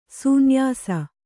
♪ sūnyāsa